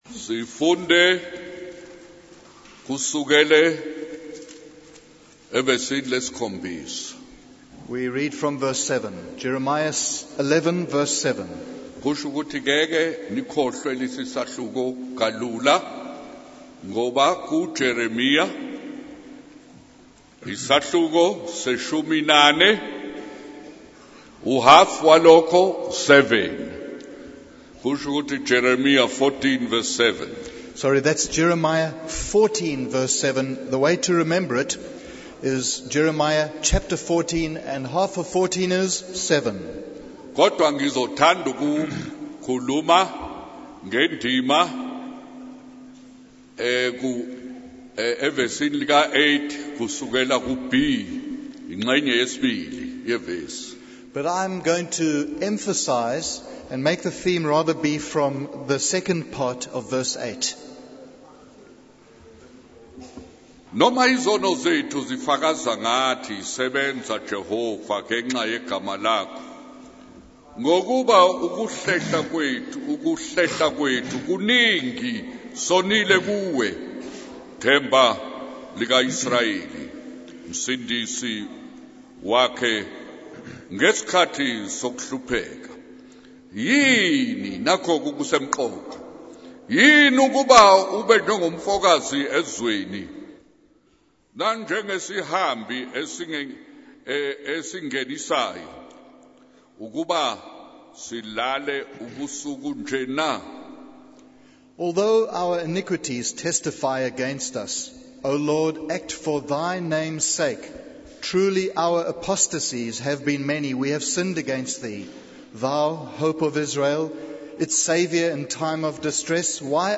In this sermon, the preacher discusses the lamentation of the people of God, Judah and Jerusalem, who were focused on their own troubles and loss but failed to recognize the evil of their own sin.